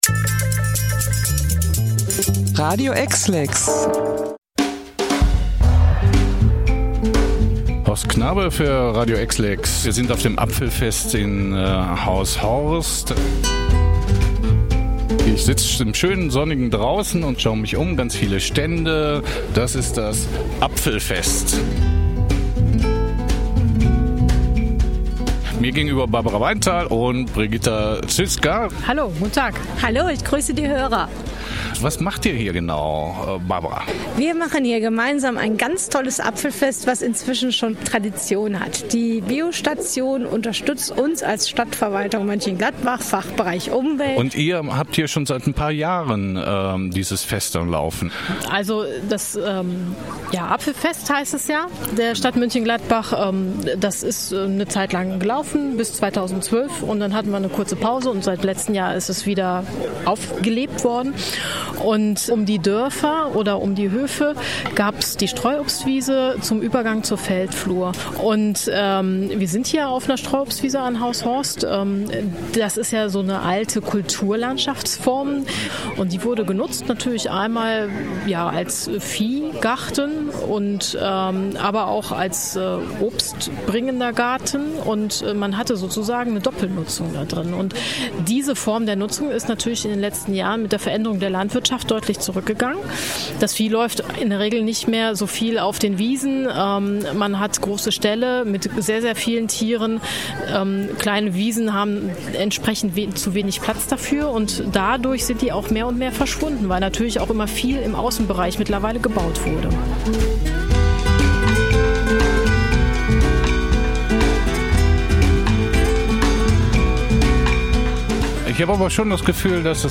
Radio-EXLEX-Interview-Apfelfest-2017.mp3